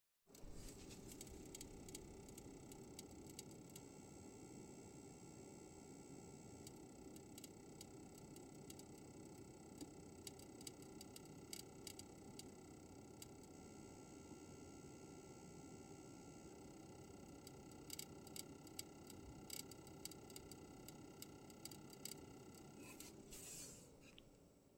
Hatte das zu anfangs erwähnt akustische Problem.
In einem Bereich von 170 - 250 Watt.
Dachte ich das in meinem Netzteil etwas bruzzelt.